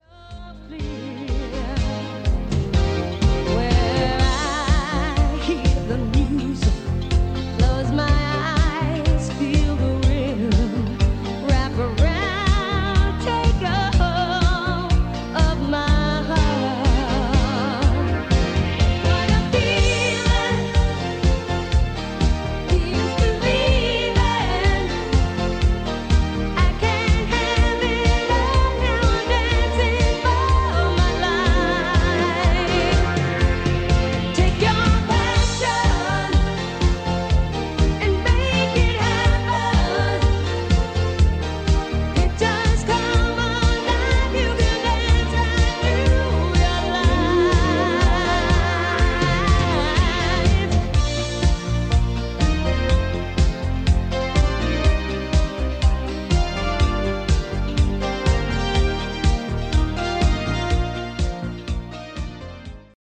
The CS-F110 is good sounding 2-head cassette deck from the early 80ties.
Below is a test recording made with the CS-F110and played back by it:
AKIA-CS-F110-Test-Recording.mp3